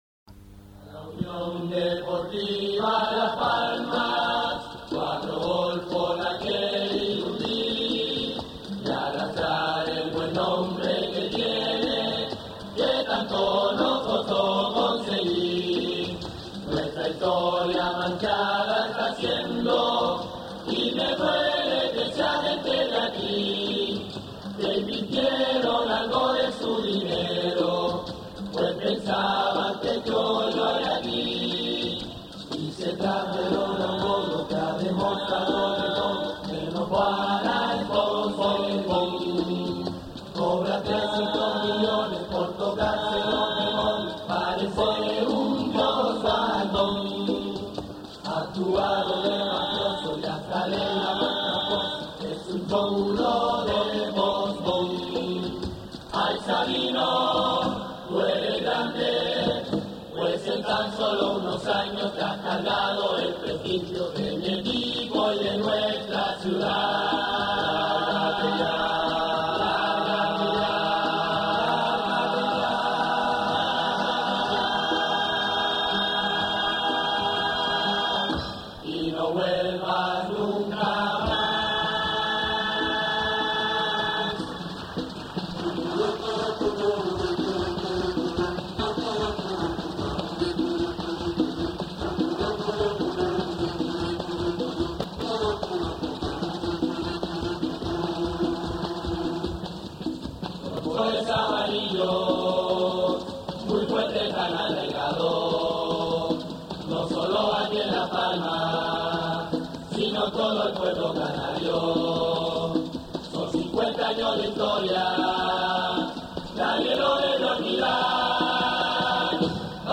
murga